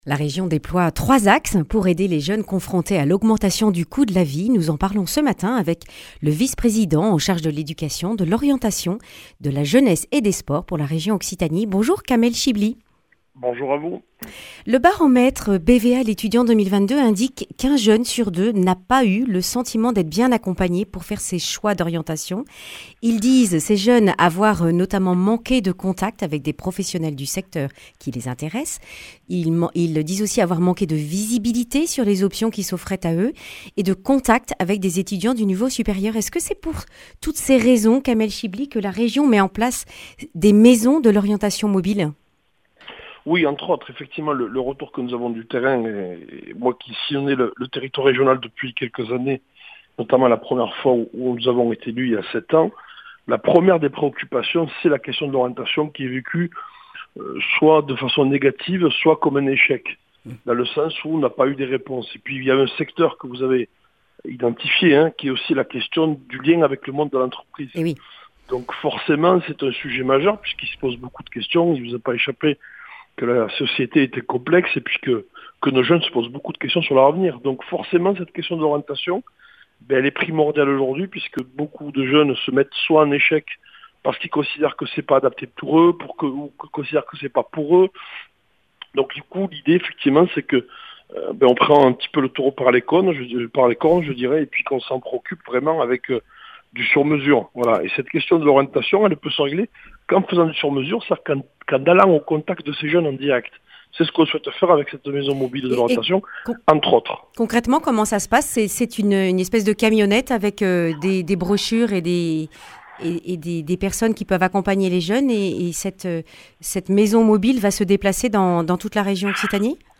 Kamel Chibli, vice-président pour la région Occitanie en charge de l’éducation, l’orientation, la jeunesse et les sports présente les dispositifs d’aide aux lycéens.
Accueil \ Emissions \ Information \ Régionale \ Le grand entretien \ Orientation, pouvoir d’achat, environnement : la Région Occitanie se met en (…)